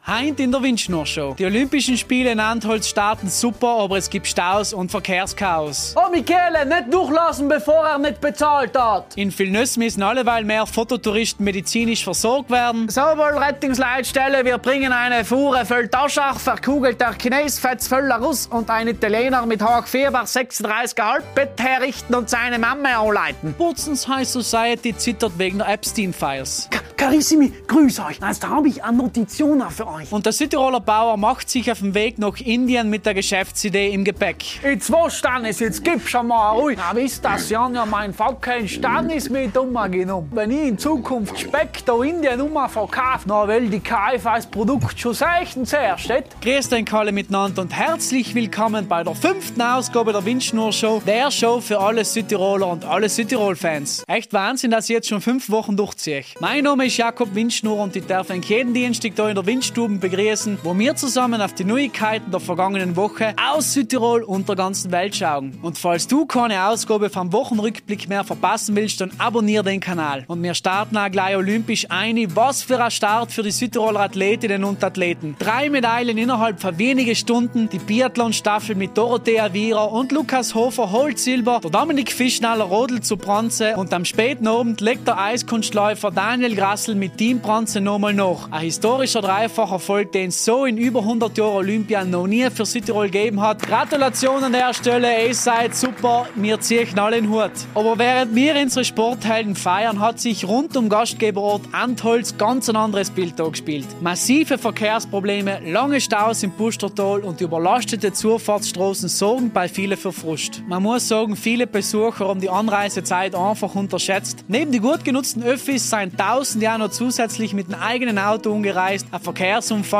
In der Windstubm blicken wir jede Woche humorvoll, pointiert und mit Dialekt auf das aktuelle Geschehen in Südtirol und der Welt.